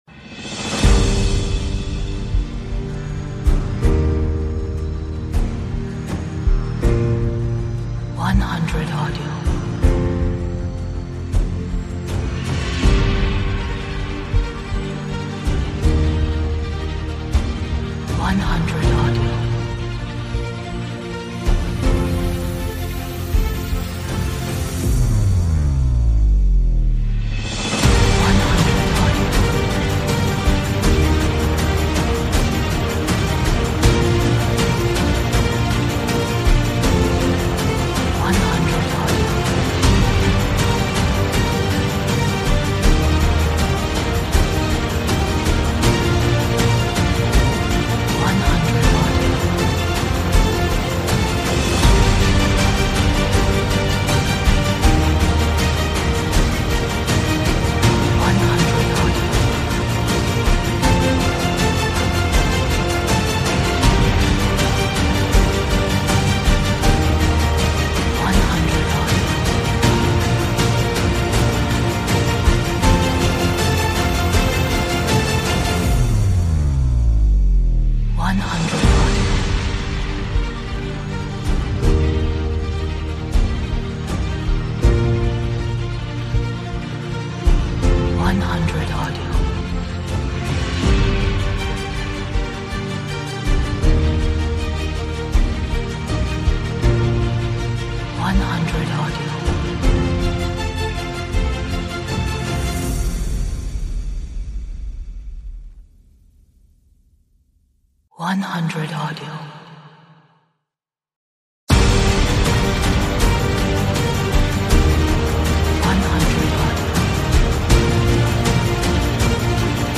Atmospheric, dramatic, epic, energetic, romantic,